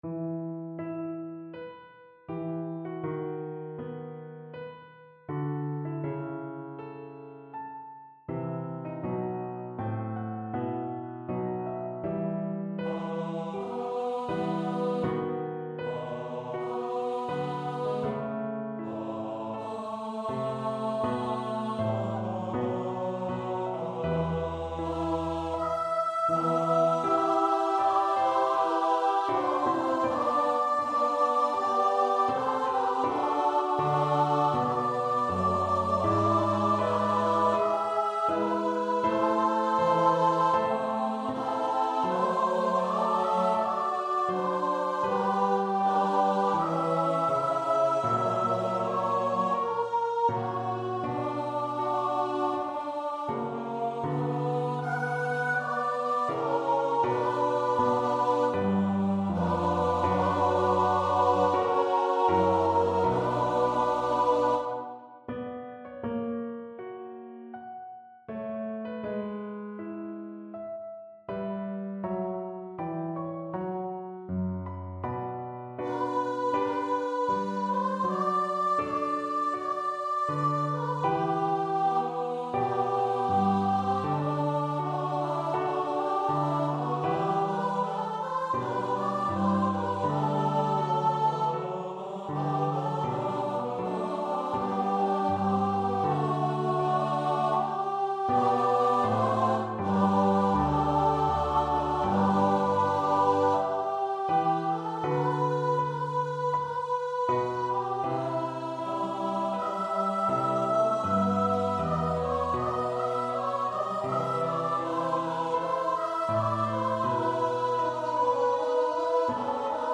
How soon our tow'ring hopes are cross'd (Joshua) Choir version
Choir  (View more Intermediate Choir Music)
Classical (View more Classical Choir Music)